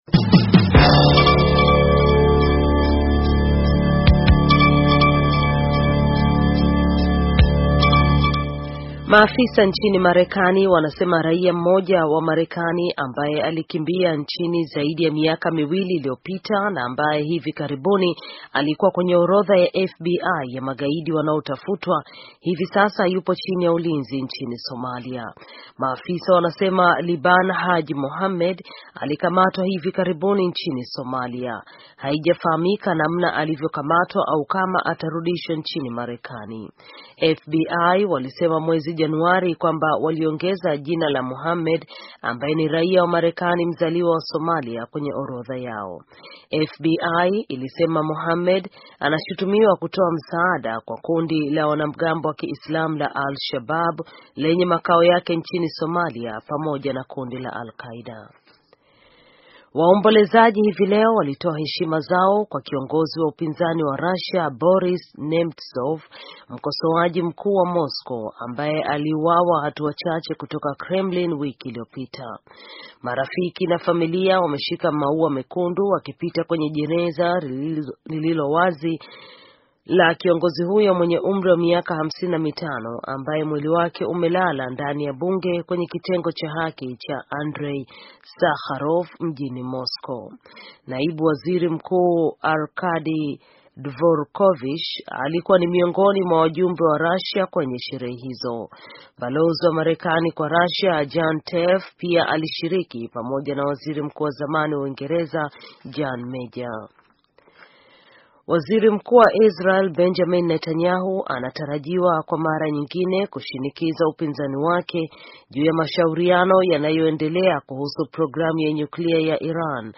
Taarifa ya habari - 5:12